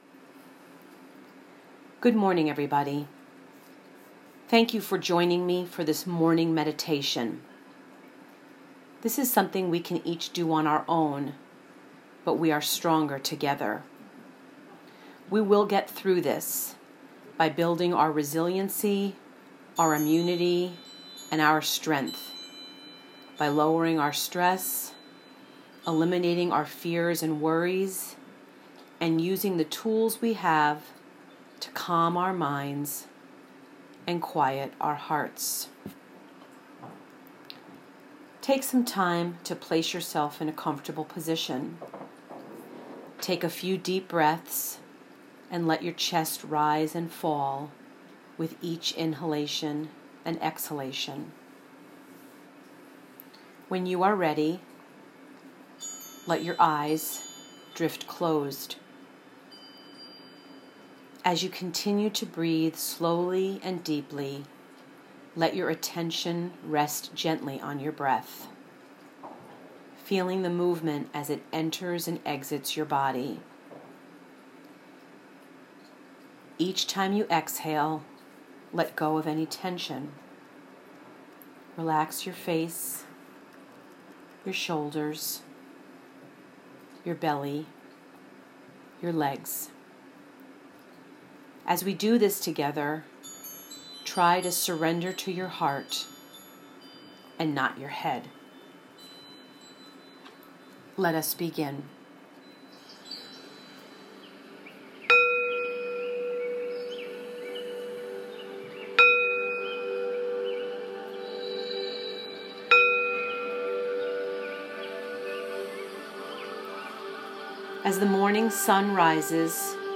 Morning_Meditation.m4a